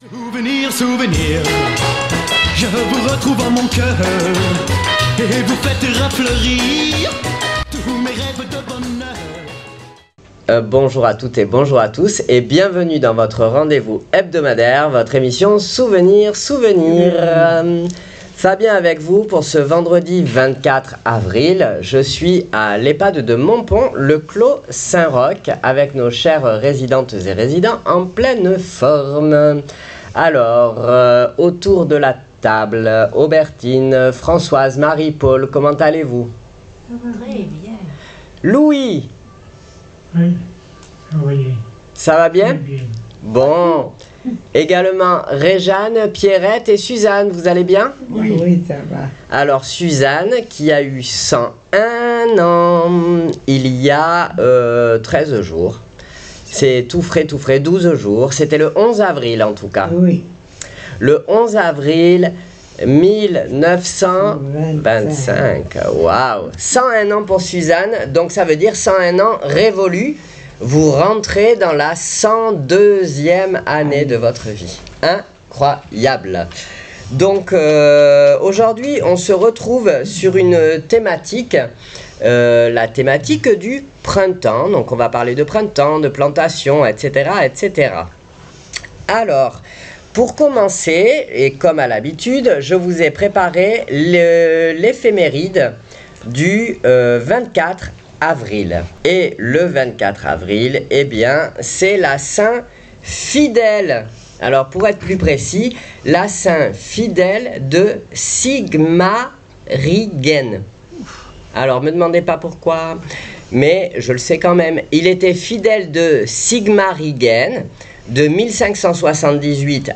à l'Ehpad de Montpon, Le Clos Saint Roch, avec nos chers résidents en pleine forme pour notre émission du Vendredi 24 avril 2026.